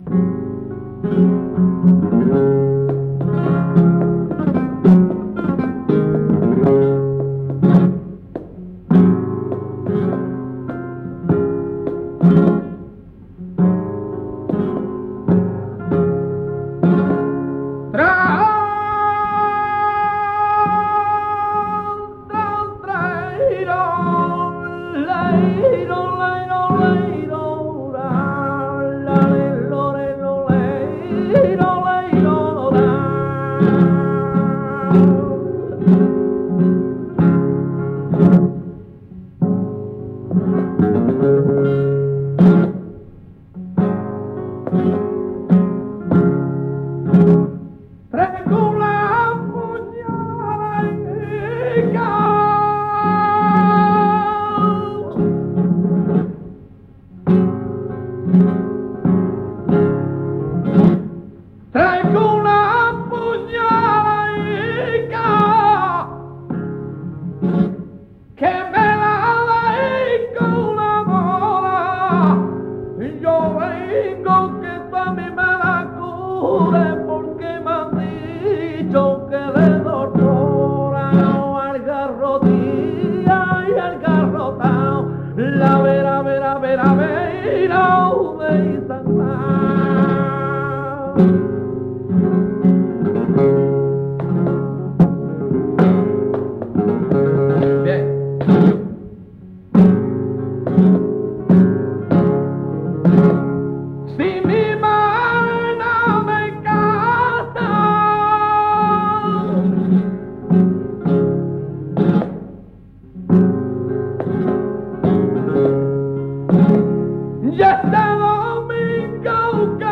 Malgré leur piètre qualité sonore, ces témoignages enregistrés à la Peña de la Platería de Grenade en 1976
6) Garrotín
6_Garroti_n.mp3